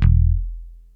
E1 4 F.BASS.wav